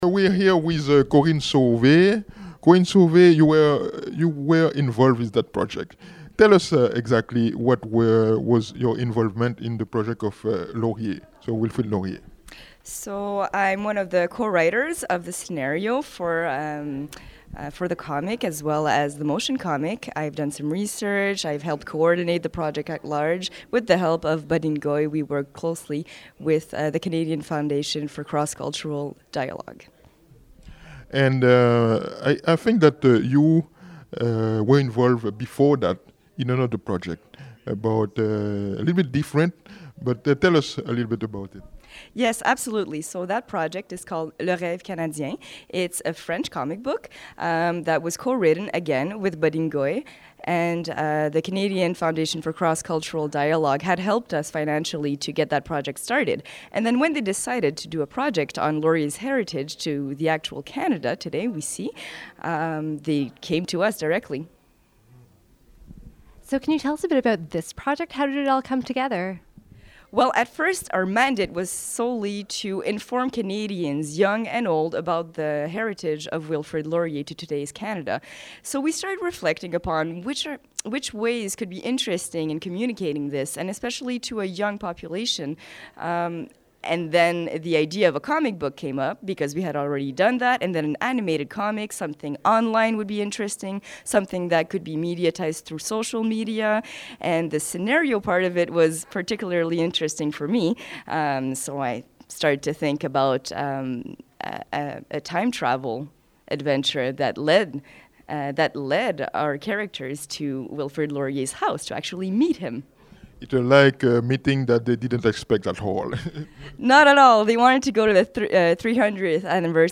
Recording Location: Ottawa
Type: Interview
320kbps Stereo